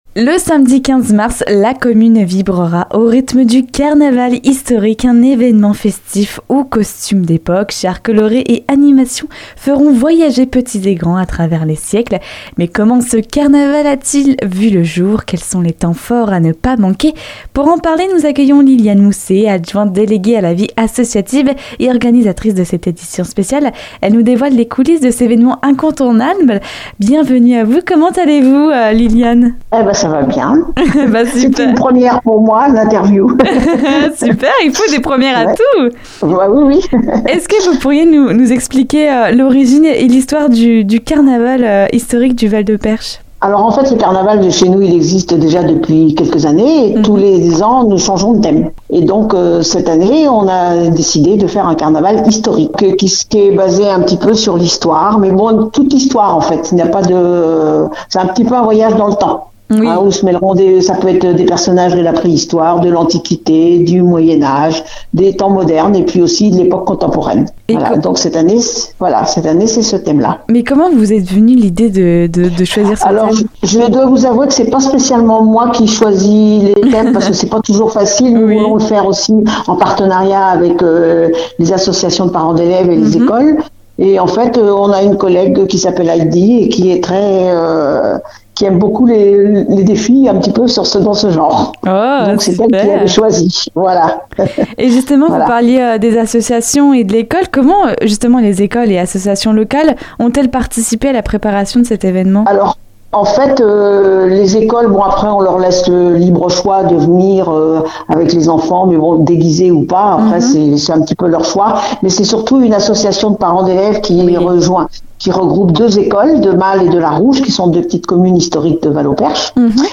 Pour en savoir plus sur cette journée festive et chaleureuse, écoutez l'interview complète avec Lyliane Mousset.